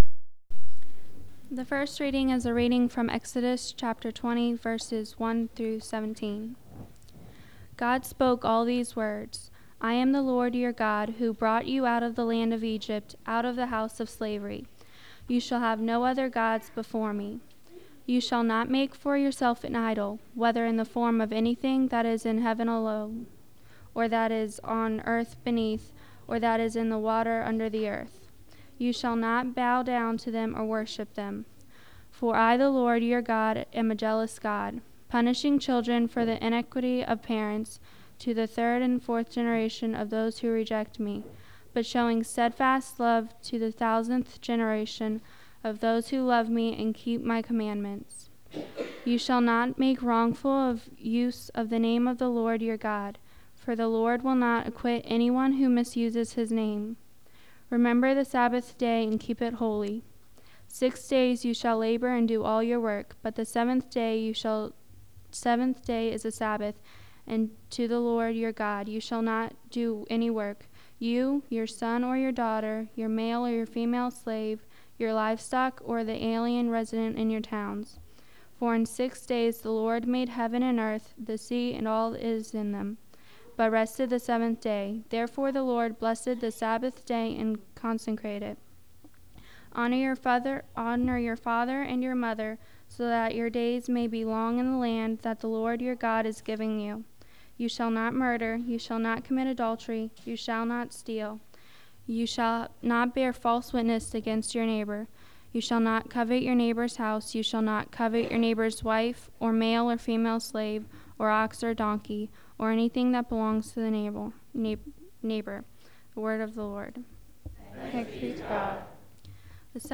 Listen to the readings, Gospel and sermon from our March 11 service.
march-11th-service.wav